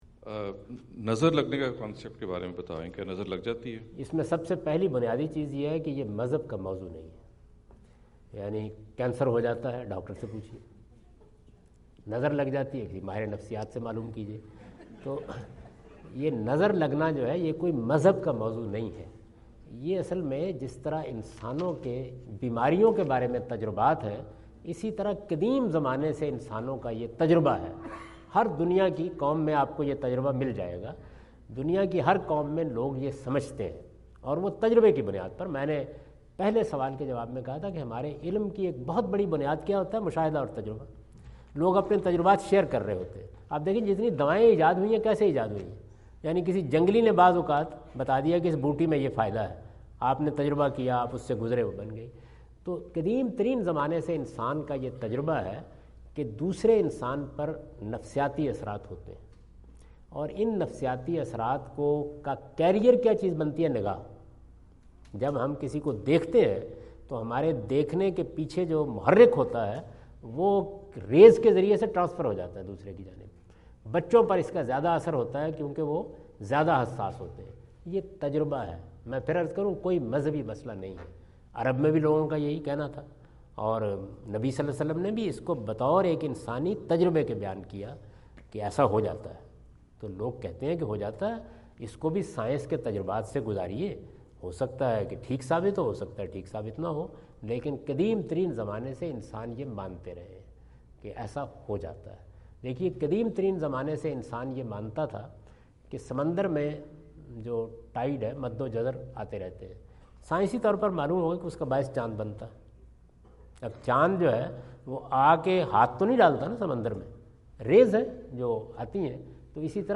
Javed Ahmad Ghamidi answer the question about "Evil Eye" asked at Aapna Event Hall, Orlando, Florida on October 14, 2017.
جاوید احمد غامدی اپنے دورہ امریکہ 2017 کے دوران آرلینڈو (فلوریڈا) میں "نظر کا لگنا" سے متعلق ایک سوال کا جواب دے رہے ہیں۔